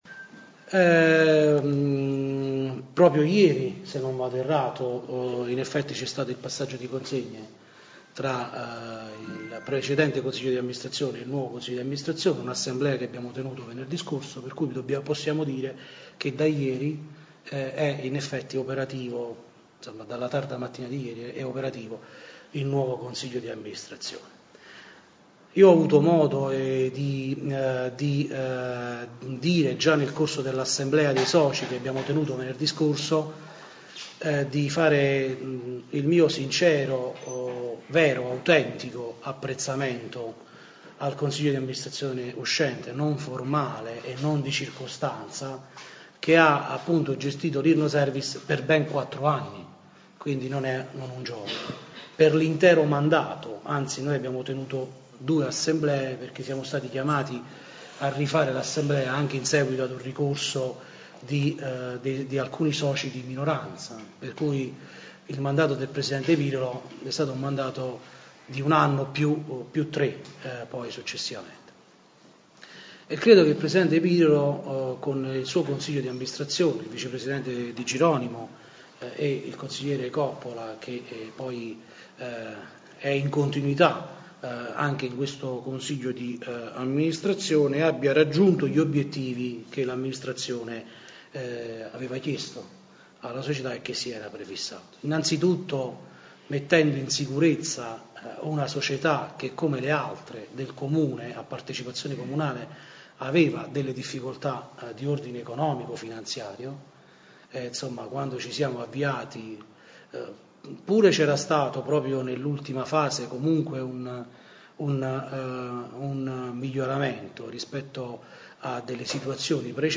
Oggi Venerdì 4/11/16 presso il complesso monumentale di Santa Chiara in Solofra ( Av) è stata presentata la Solofra Servizi s.p.a società che subentra alla Irno Service s.p.a. Alla presentazione il primo cittadino Michele Vignola
RIASCOLTA LA CONFERENZA STAMPA: